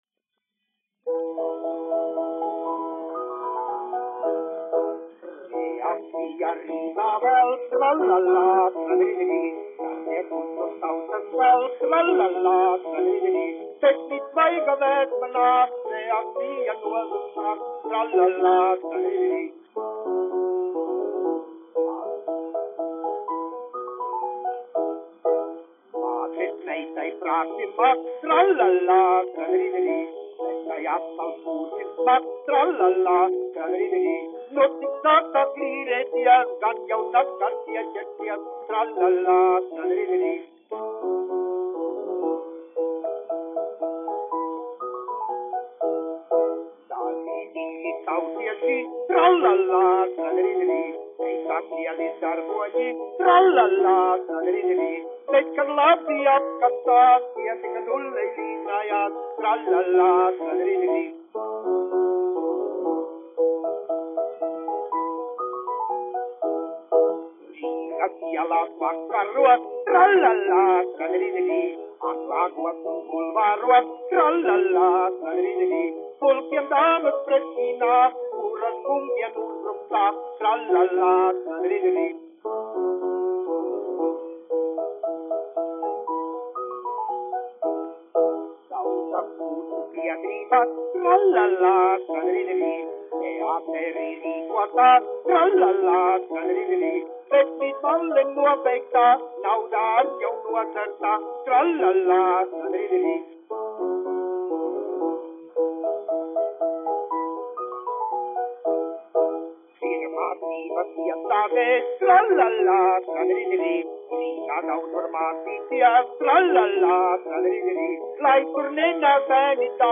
1 skpl. : analogs, 78 apgr/min, mono ; 25 cm
Dziesmas ar klavierēm
Populārā mūzika
Latvijas vēsturiskie šellaka skaņuplašu ieraksti (Kolekcija)